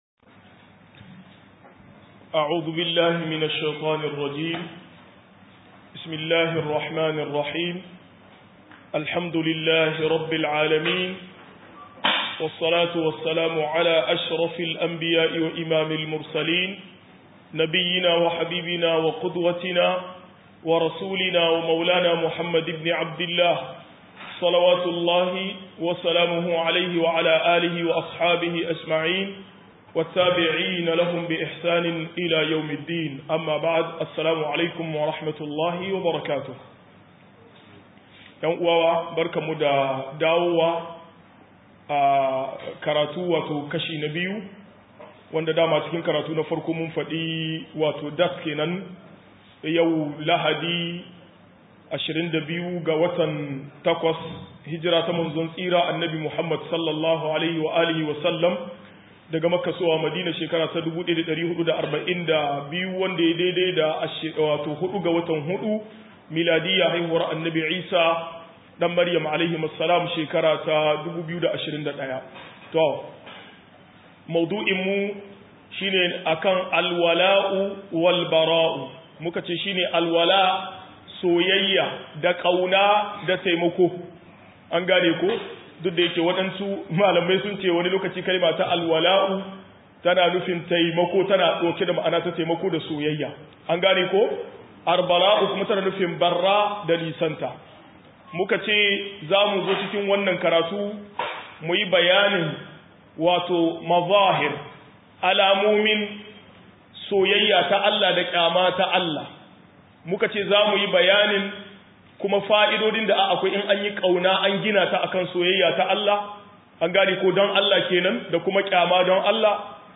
121-Soyayya Da Ki Don Allah 2 - MUHADARA